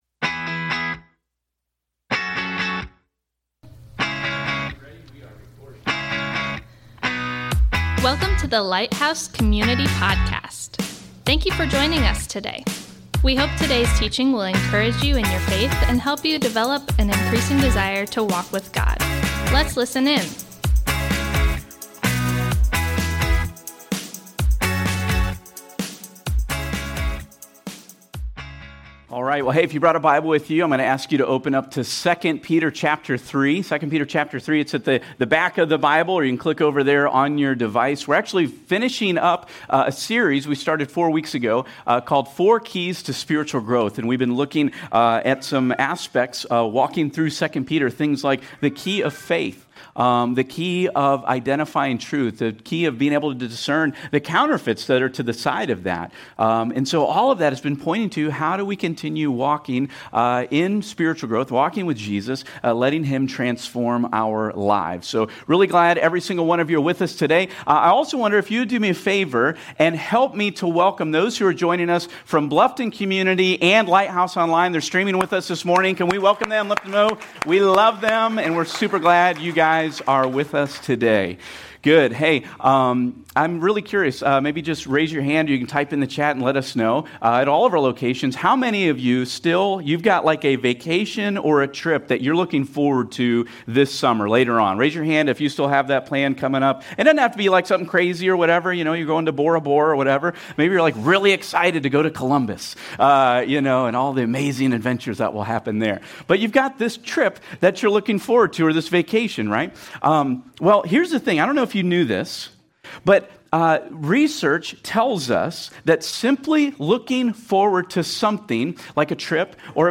Thanks for joining us today as we worship together.